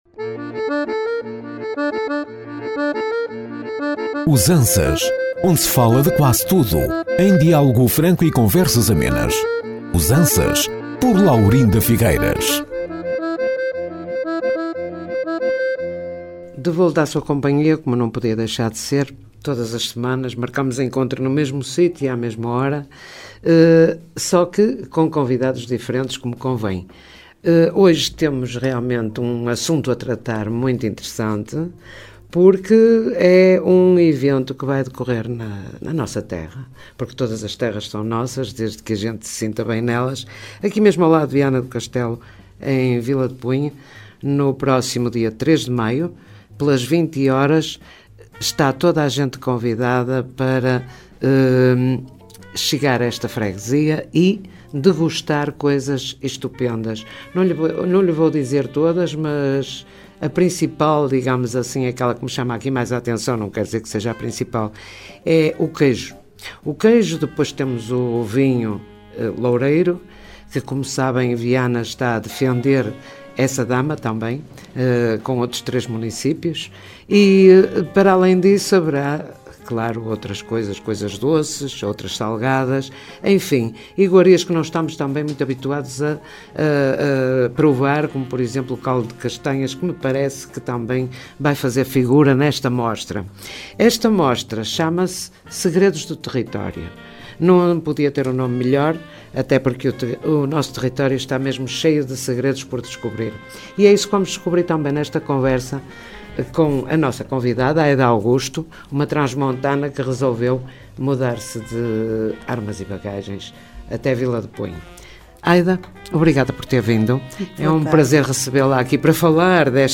Um espaço de conversas amenas, de partilha, de costumes e de opinião, provocando olhares atentos.